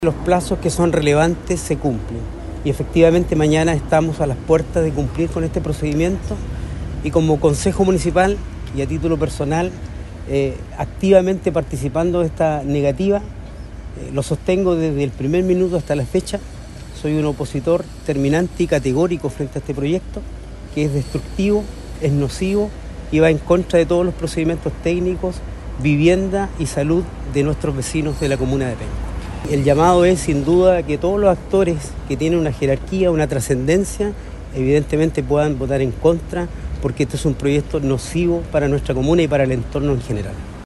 El concejal Fernando Torres, en tanto, criticó la estrategia comunicacional de la empresa, tildándola de ineficaz ante la postura ciudadana.